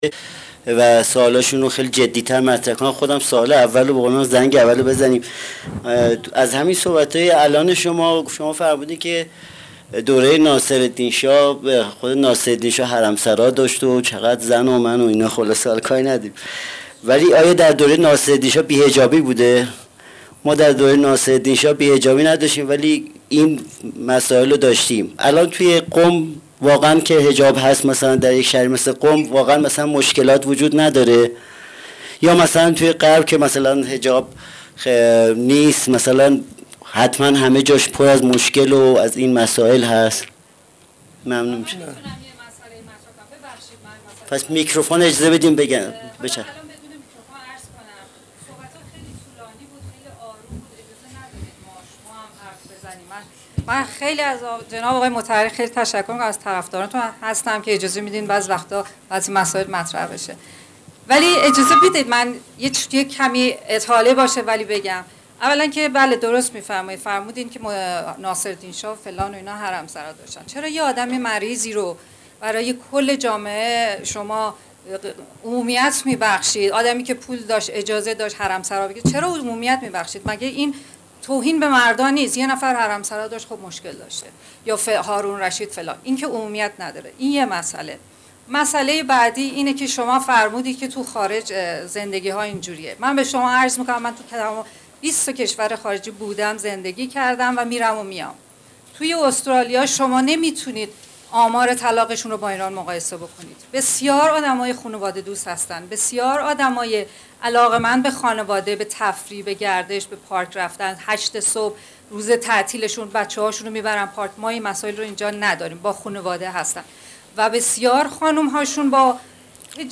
سالن حکمت